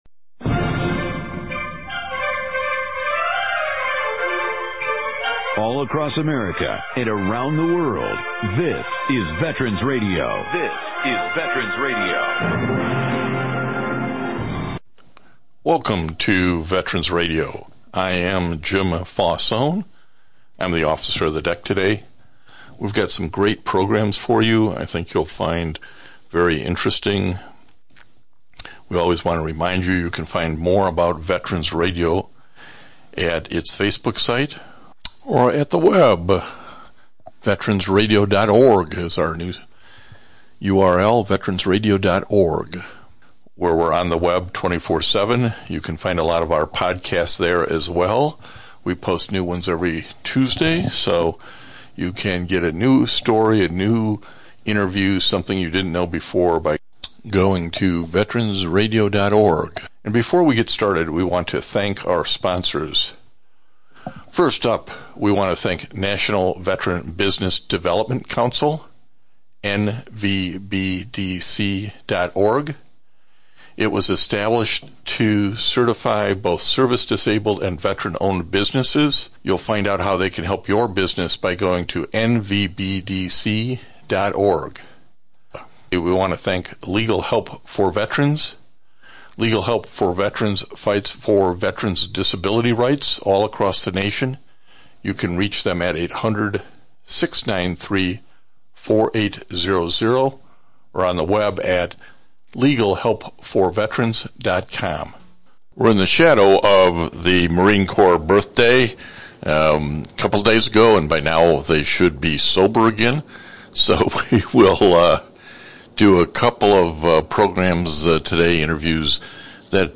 Camp LeJeune Claims Overwhelm DoD The second interview is about a Camp LeJeune water contamination policy guidance put out by the Navy to try to settle the 100,000+ cases.